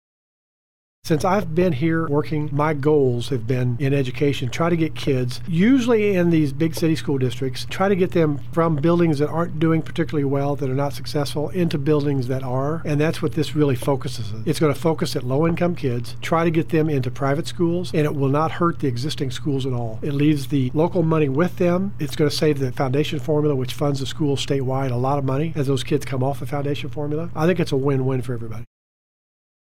2. Senator Cierpiot adds education is very important to him.